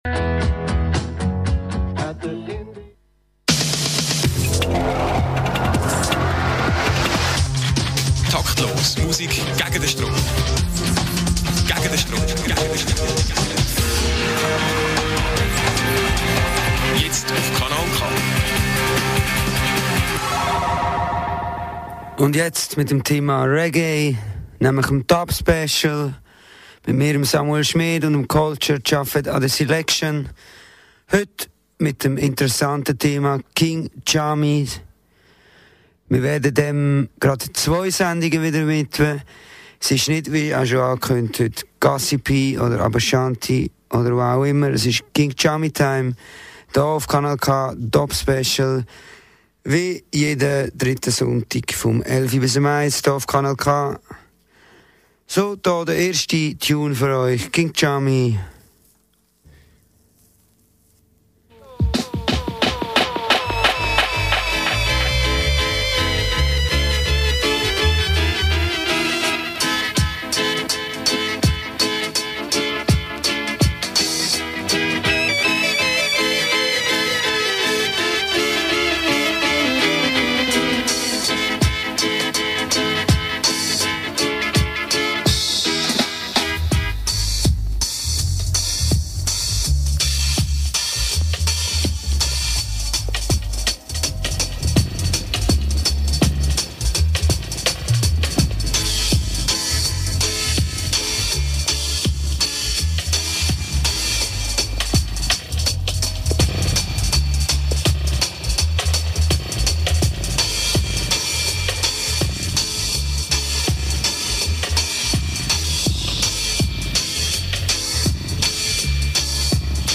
moderated in german